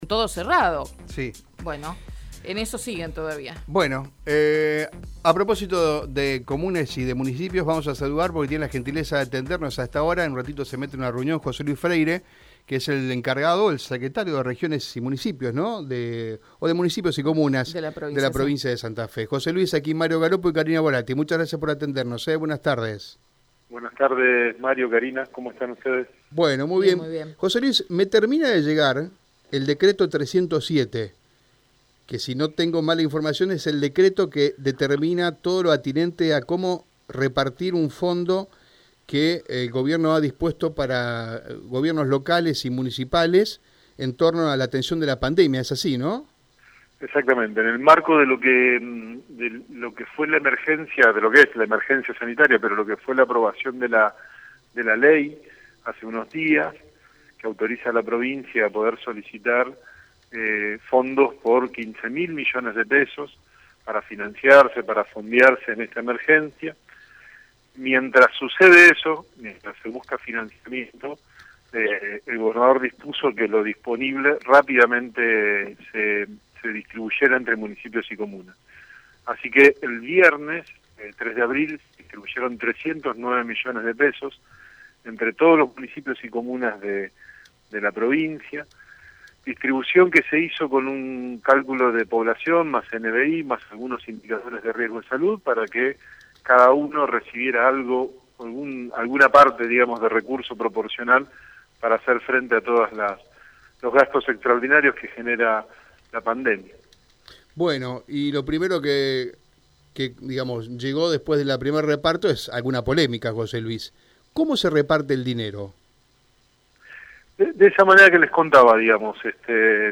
En Radio Eme dialogamos con José Luis Freyre, secretario de Municipios de la Provincia, sobre el decreto 307 que reparte el dinero para la atención de gobierno locales para atender necesidades derivadas de los efectos de la pandemia y emergencia sanitaria, social y alimentaria.